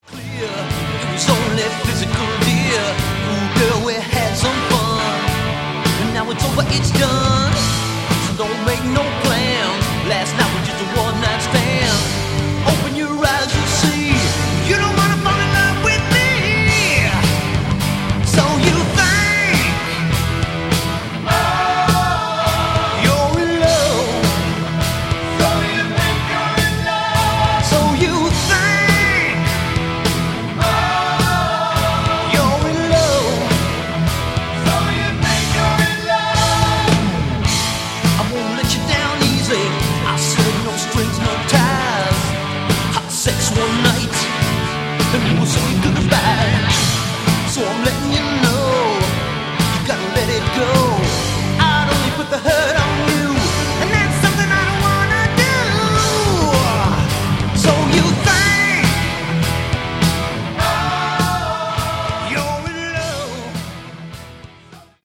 Category: Hard Rock
drums, percussion
lead guitar
bass, backing vocals
lead vocals, rthythm guitar
keyboards, backing vocals